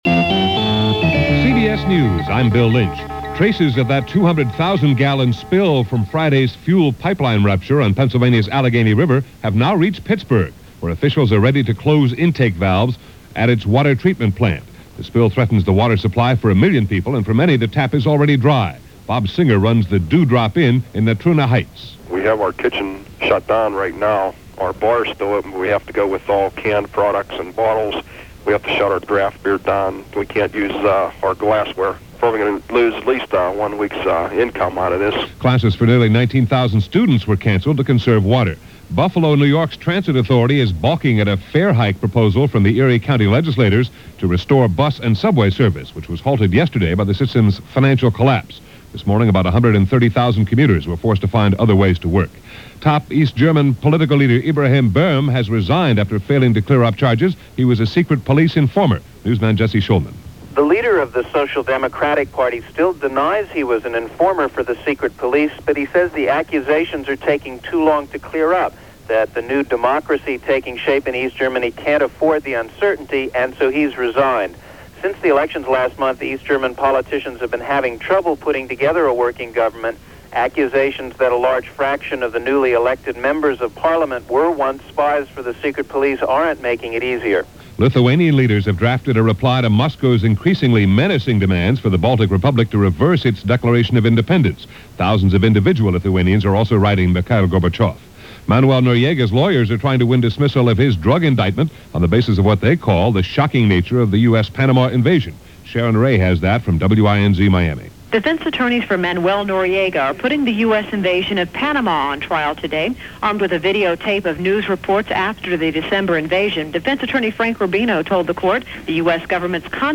And on it went – this April 2nd in 1990 as presented by CBS Radio‘s Hourly News.